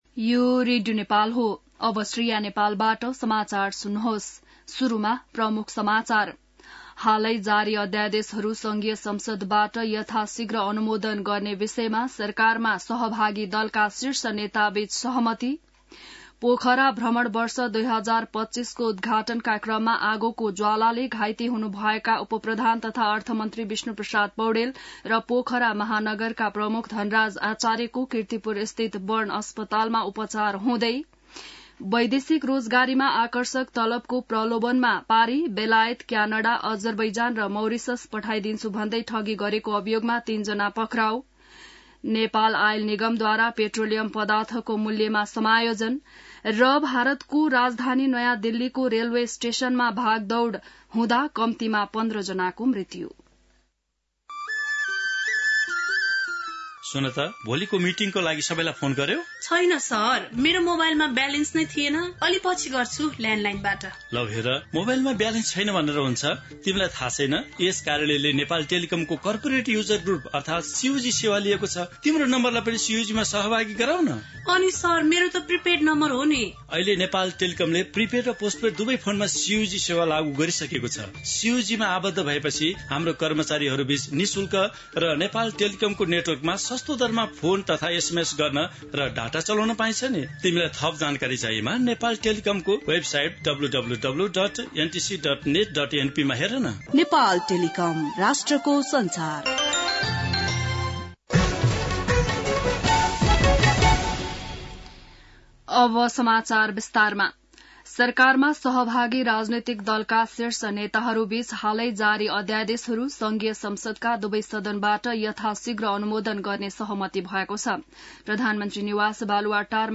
बिहान ७ बजेको नेपाली समाचार : ५ फागुन , २०८१